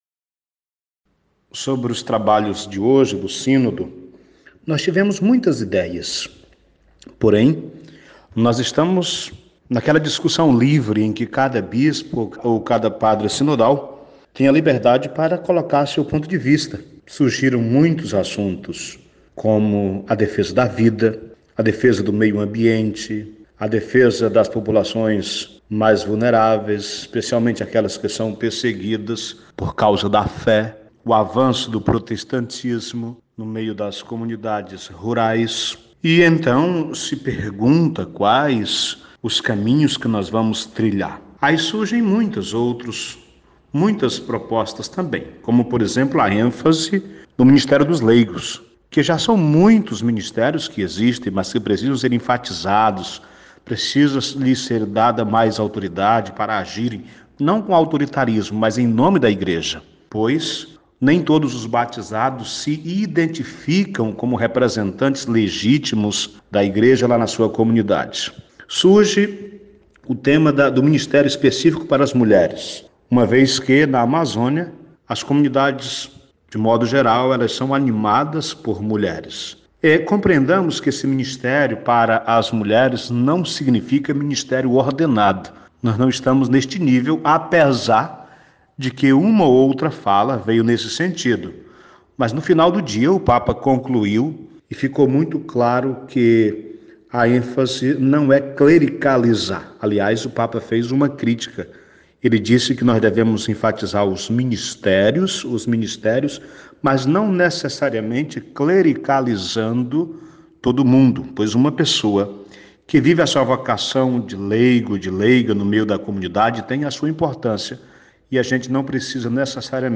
presente no Sínodo dos Bispos traz um panorama das discussões desta quarta-feira, no Vaticano.